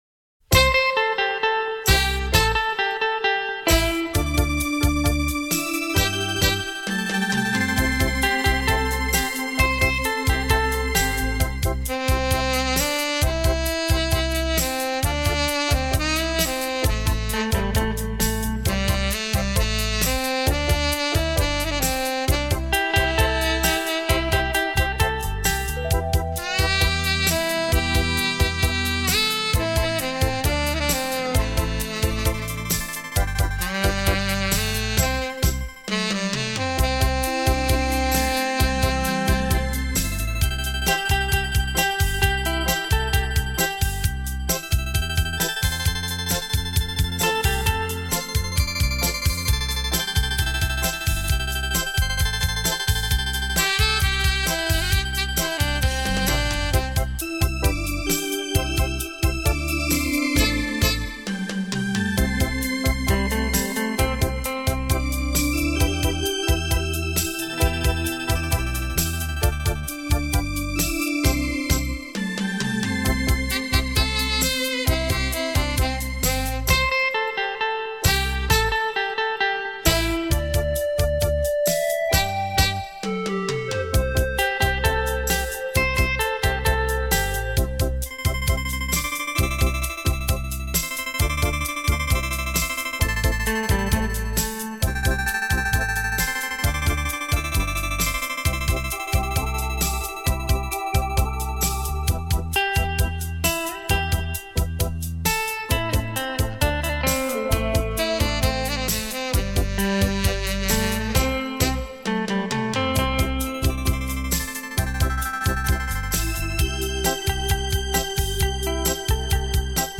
雷射版[音响测试带] 现场演奏